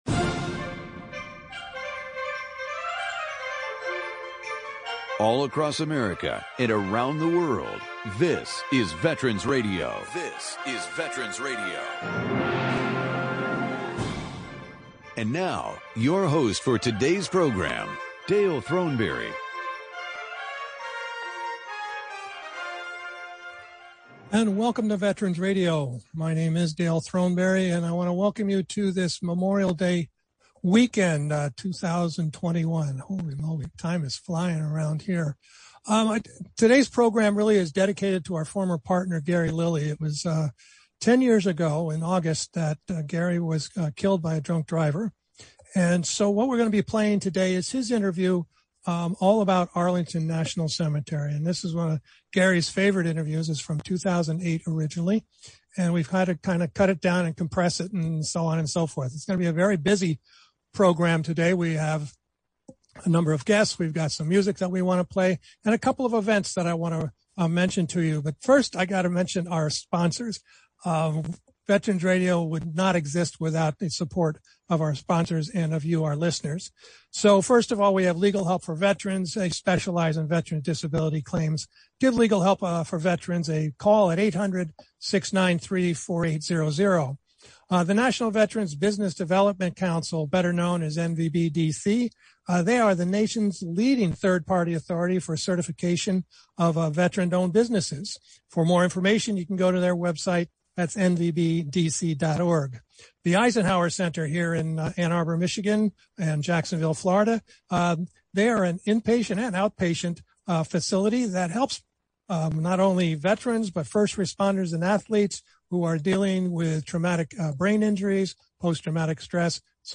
Special Memorial Day Encore Presentation from 2008